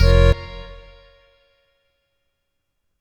GUnit Synth10.wav